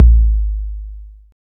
Index of /90_sSampleCDs/Roland L-CD701/DRM_Drum Machine/KIT_TR-808 Kit